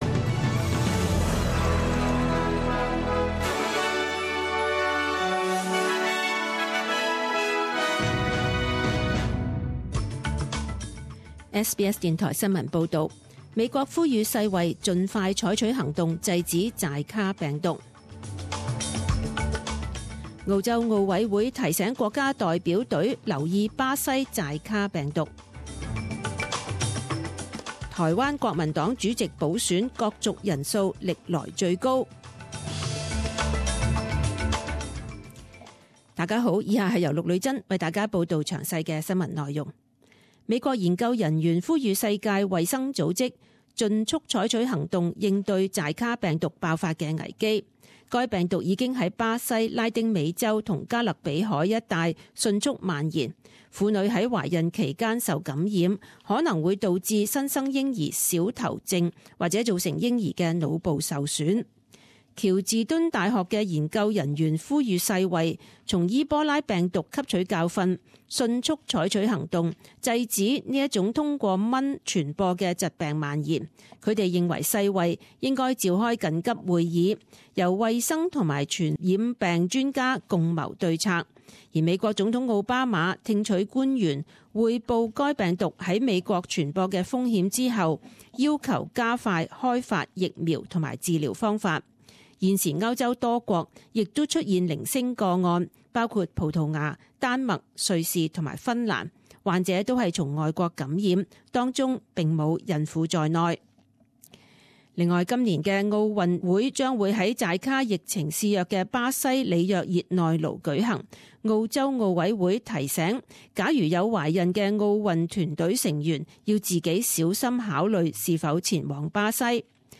十点钟新闻报导（一月二十八日）
请收听本台为大家准备的详尽早晨新闻。